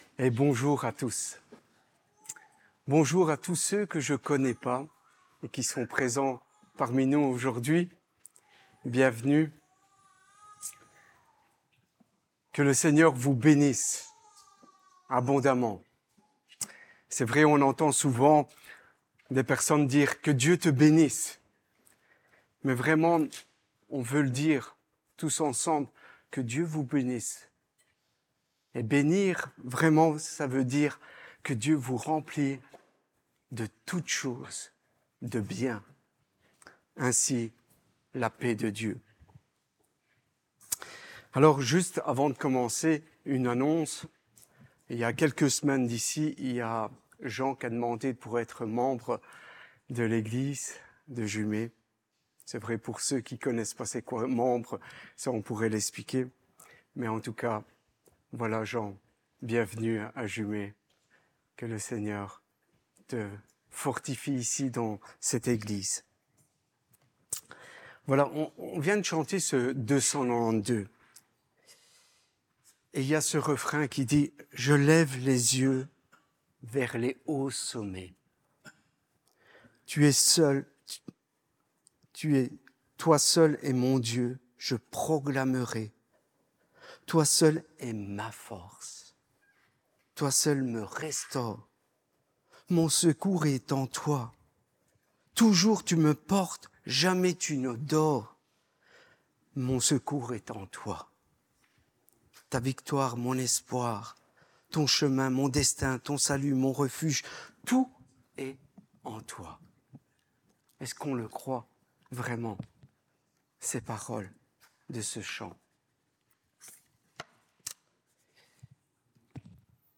Être dans le plan de Dieu Prédication